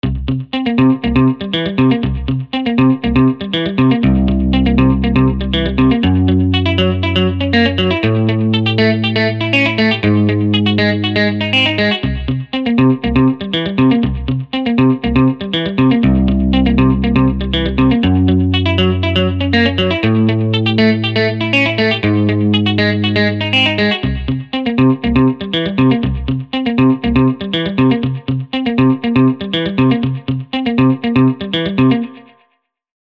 These were all made using ManyGuitar: there has been no external processing (except a touch of compression/limiting).
ManyGuitar - Tele picking
ManyguitarTele1.mp3